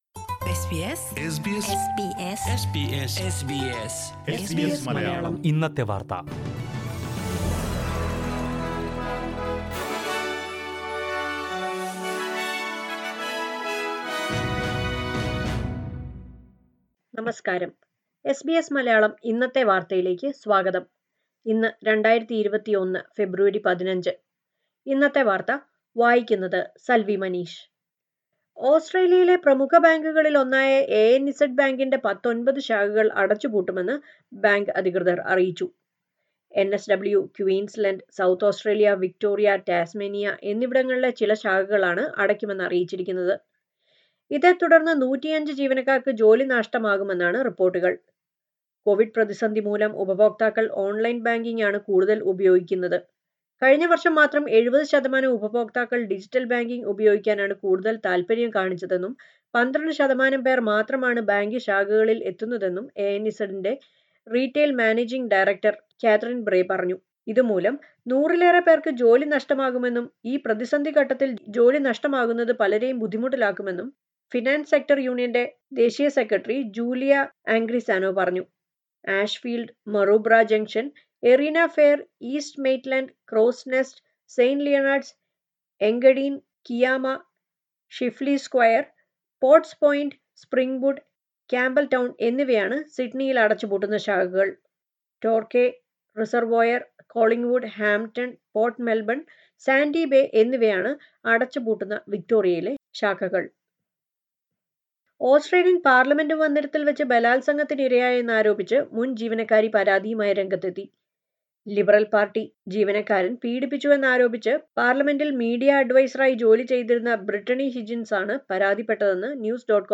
2021 ഫെബ്രുവരി 15ലെ ഓസ്ട്രേലിയയിലെ ഏറ്റവും പ്രധാന വാർത്തകൾ കേൾക്കാം